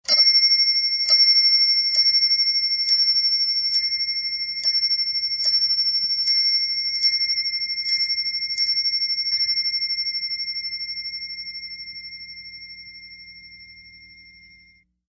Miniature Clock | Sneak On The Lot
CLOCKS MINIATURE CLOCK: INT: Miniature clock bell chimes and strikes 7 0`clock, long bell ring fade.